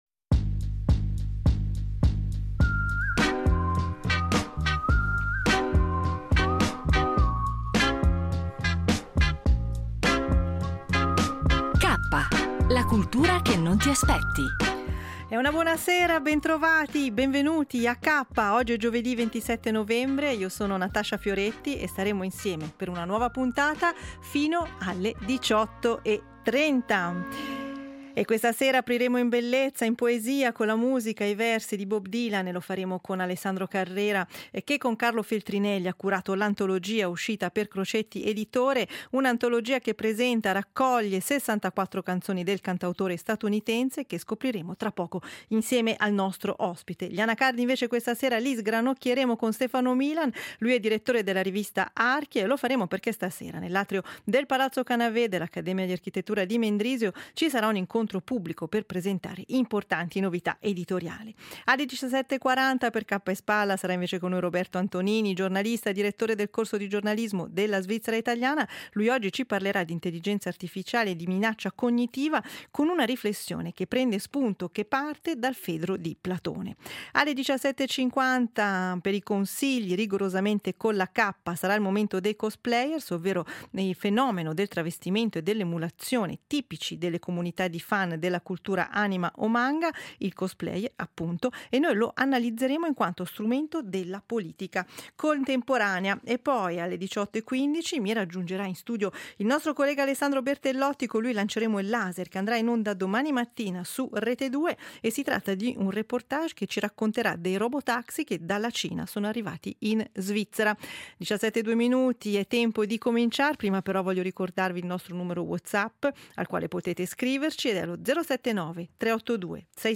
Viene proposta un’intervista